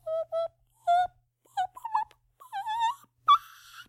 描述：在等待加载的东西时发出奇怪的声音
Tag: 语音 BOOP